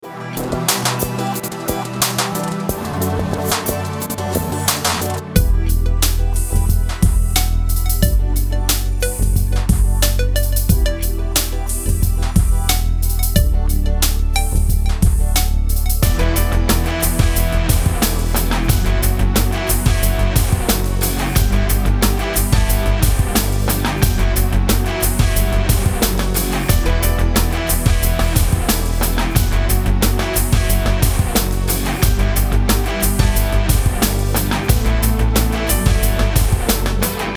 Pista musical Estilo: Urbano Duración
Calidad de la muestra (48kbps) ⬅Dale click al Play
Pista musical para jingles